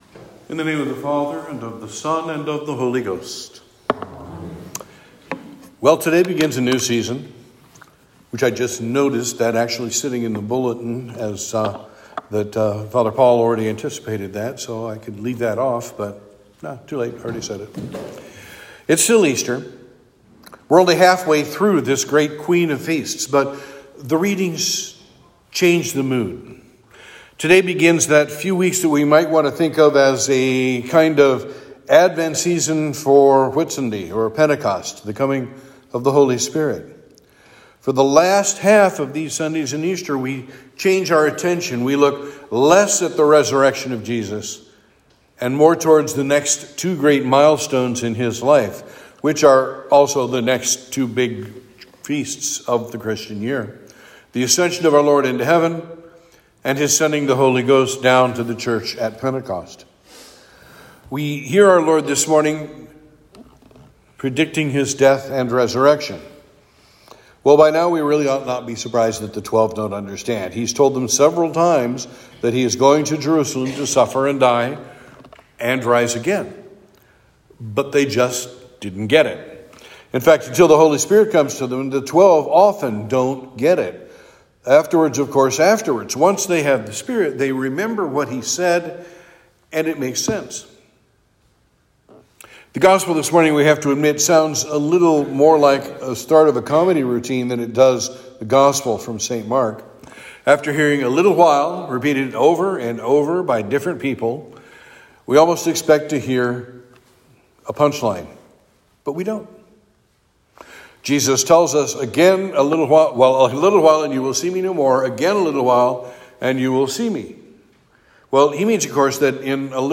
Sermon for Easter 3